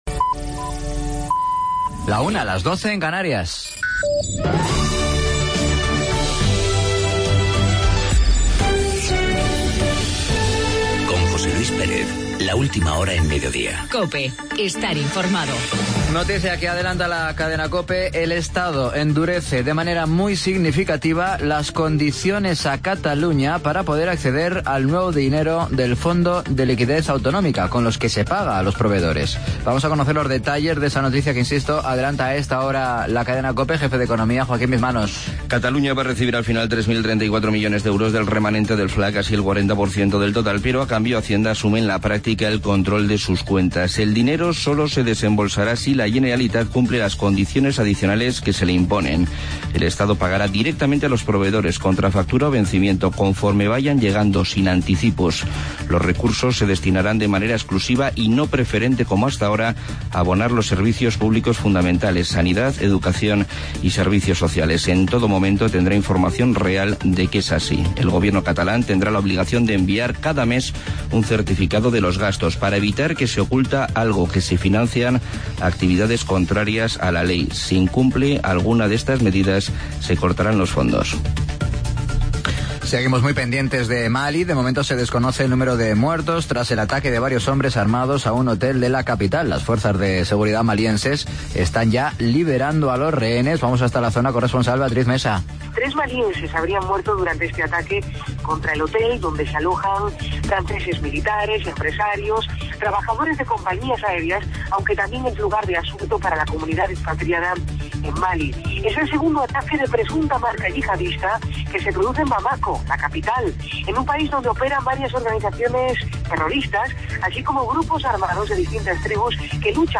AUDIO: Boletín informativo, Laura Álvarez (concejala Cultura de Bembibre), necrológicas y avance deportivo.